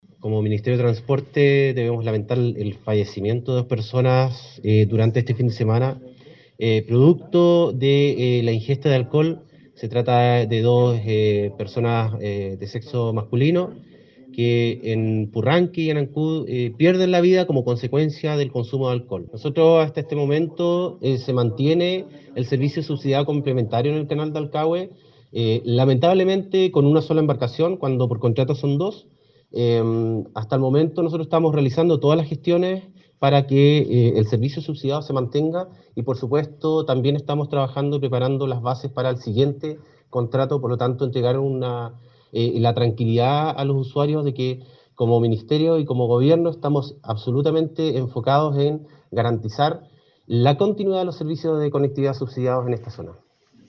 Durante este fin de semana dos personas murieron a causa de accidentes de tránsito en la región de Los Lagos, lamentó el seremi de Transportes Pablo Joost, lo que tuvo lugar en Purranque y en Ancud.